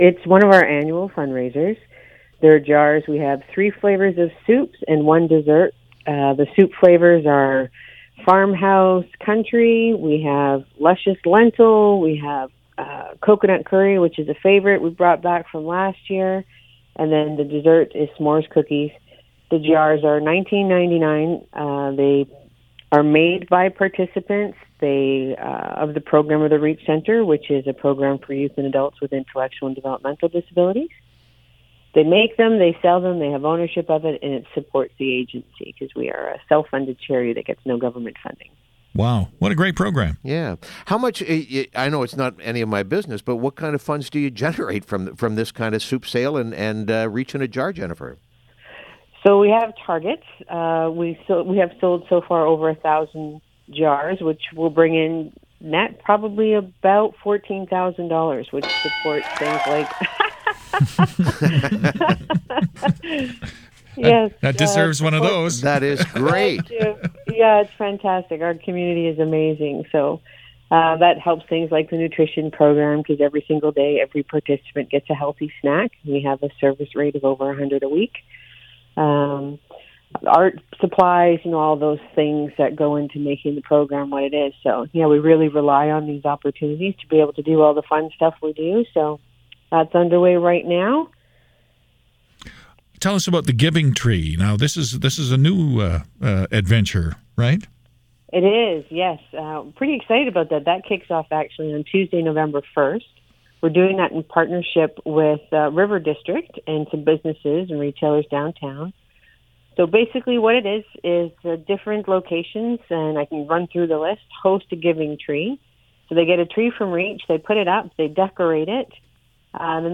560 CFOS Morning Show